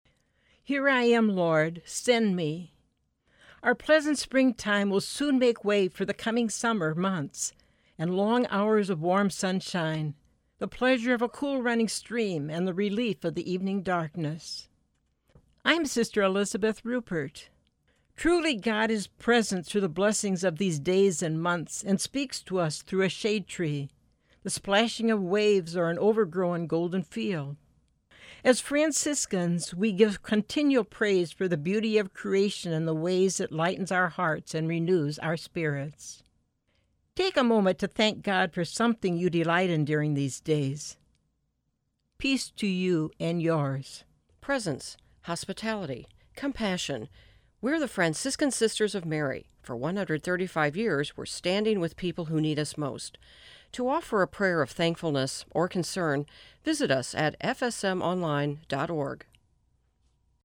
Each month, FSM share a one-minute message of hope, joy, grace or encouragement on the St. Louis classical music radio station.